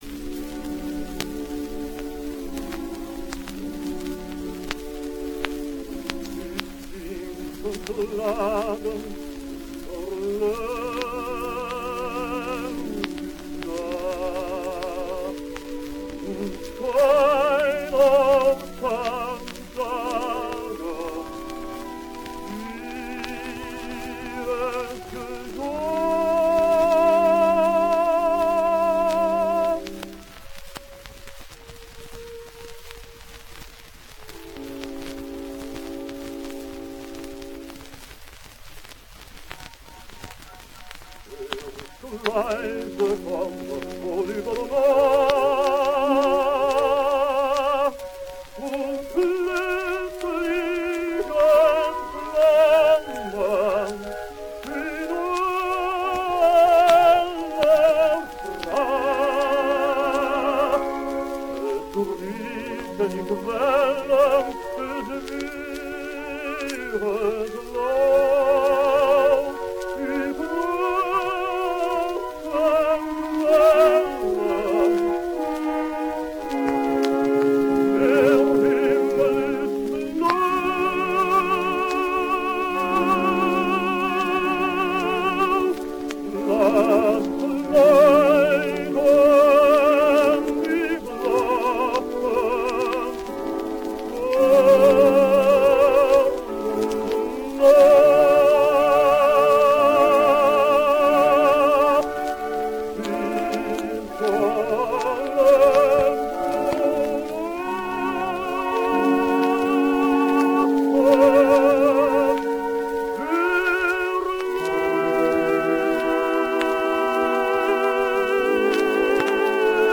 One of the most important German heldentenors